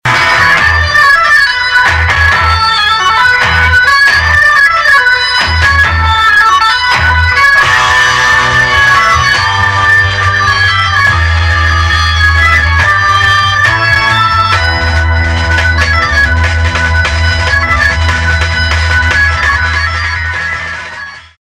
• Качество: 128, Stereo
без слов
60-е